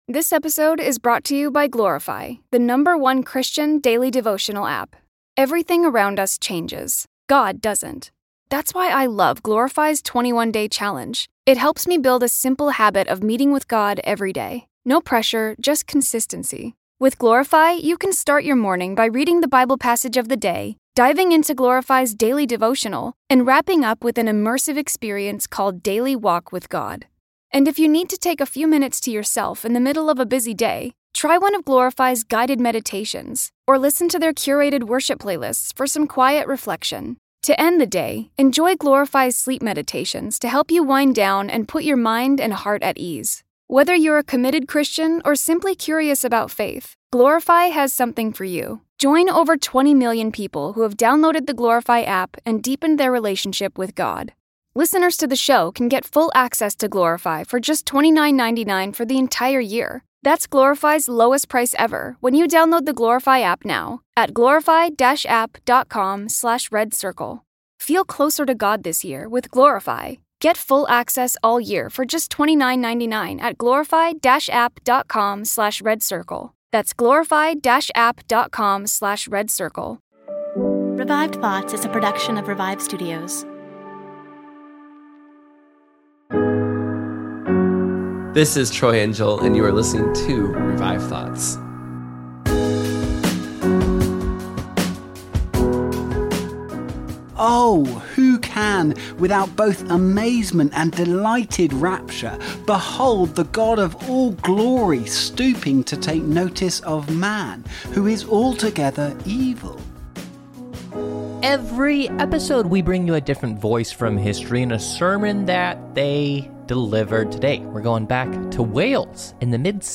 Plus his most famous sermon.